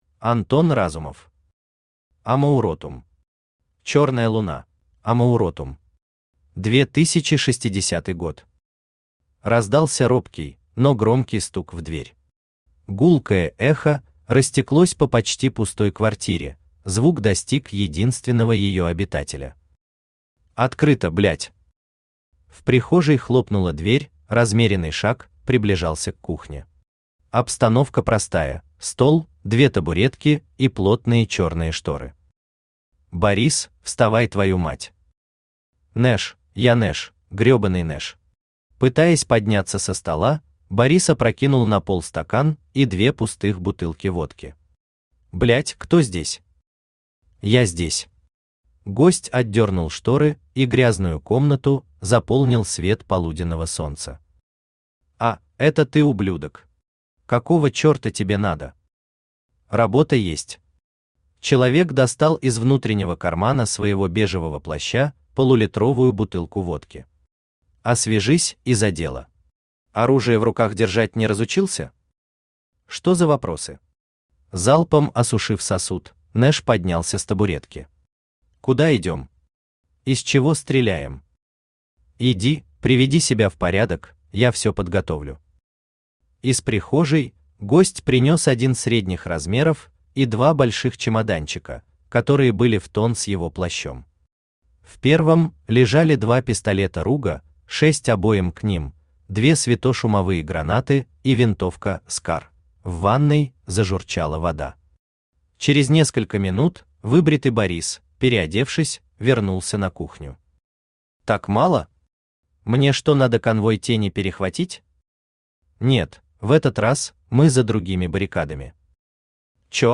Аудиокнига Амауротум. Чёрная луна | Библиотека аудиокниг
Чёрная луна Автор Антон Андреевич Разумов Читает аудиокнигу Авточтец ЛитРес.